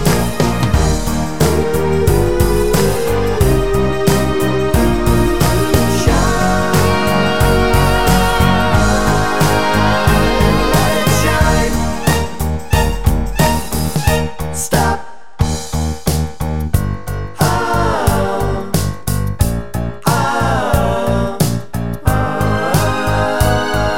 No Lead Guitar Pop (1990s) 3:30 Buy £1.50